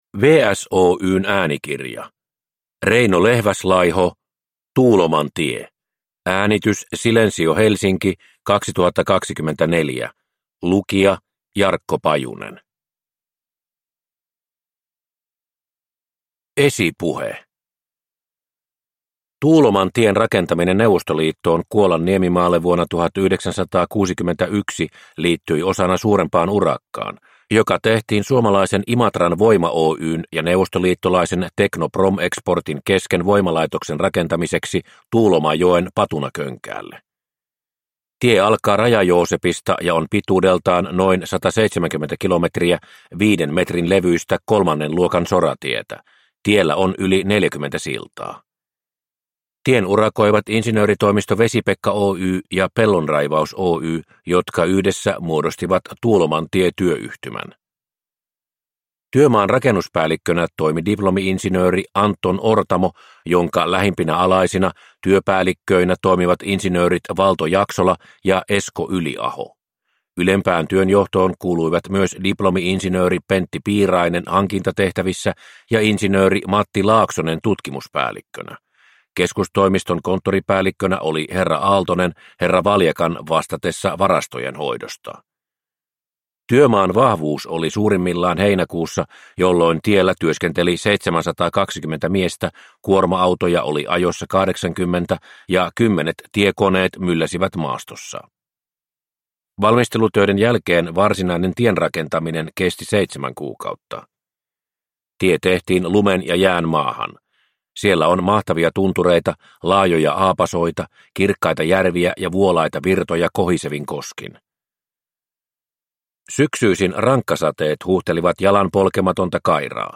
Tuuloman tie – Ljudbok